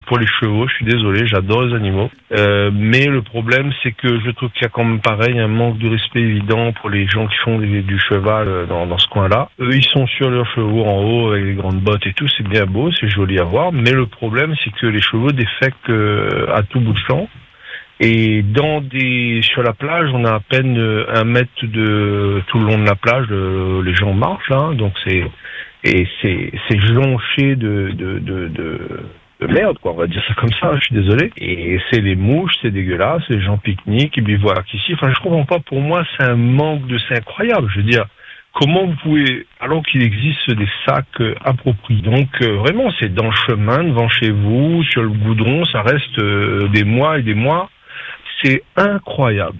Un ras-le-bol exprimé sans agressivité, mais avec fermeté, face à une situation qui dure et qui exaspère de plus en plus de monde.